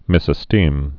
(mĭsə-stēm)